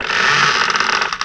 Mario 64 sound effects
door wood
door-wood.WAV